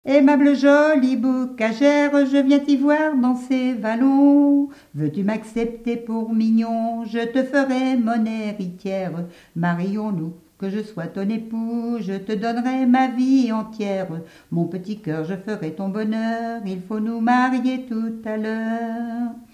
Notre-Dame-de-Riez ( Plus d'informations sur Wikipedia ) Vendée
Genre laisse
Pièce musicale inédite